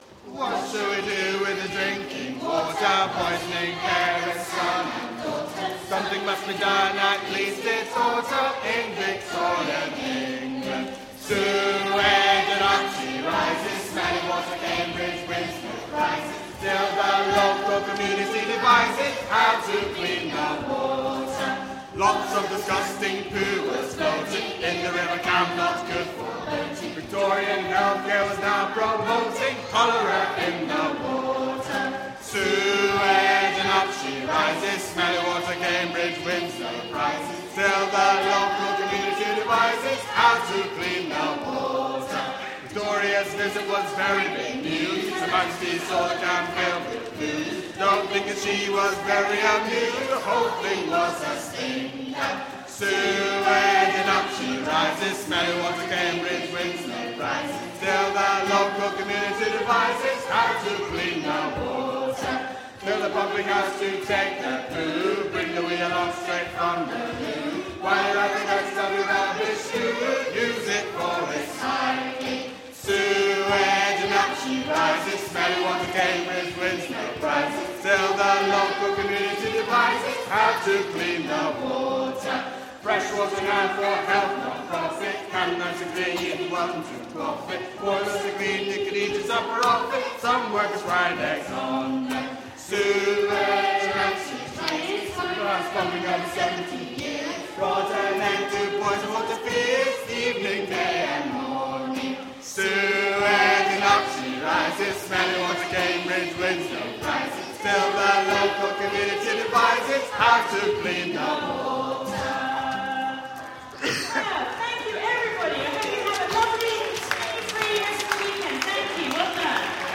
Singing History Concert 2016: What Shall We Do With the Drinking Water 2
To the tune of old folk song 'Drunken Sailor'